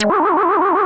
Spin's sound in Super Mario Kart.
SMK_-_Spin_(SFX).oga.mp3